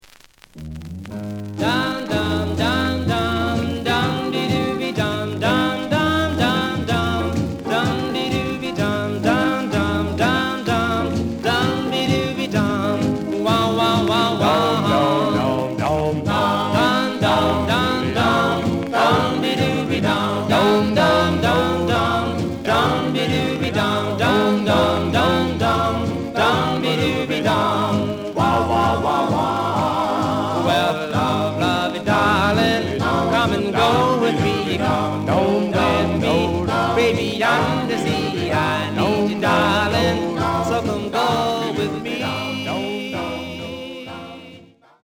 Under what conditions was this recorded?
The listen sample is recorded from the actual item. ●Format: 7 inch